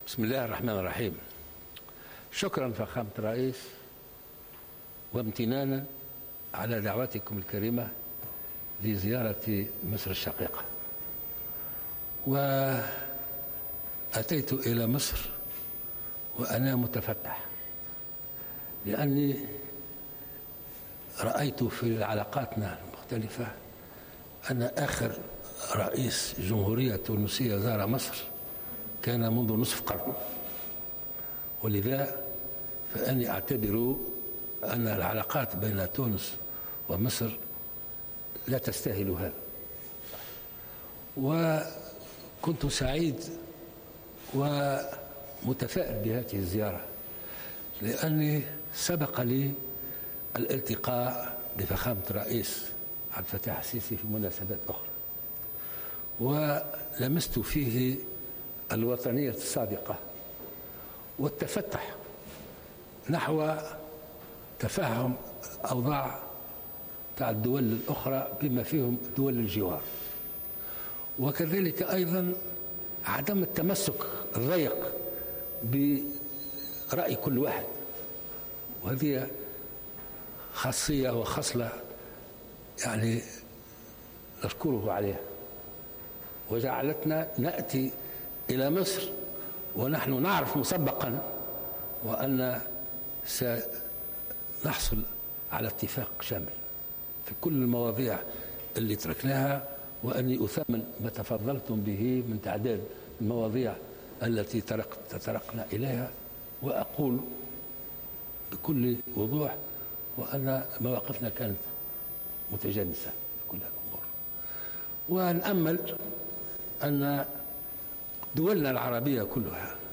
قال الباجي قائد السبسي إن انتصار مصر في حرب 6 أكتوبر 1973 على اسرائيل أعاد للجيوش والشعوب العربية كرامتها، وذلك في ندوة صحفية مشتركة مع نظيره المصري عبد الفتاح السيسي بمناسبة زيارة يؤديها حاليا إلى مصر.